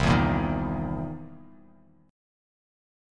C7#9 Chord
Listen to C7#9 strummed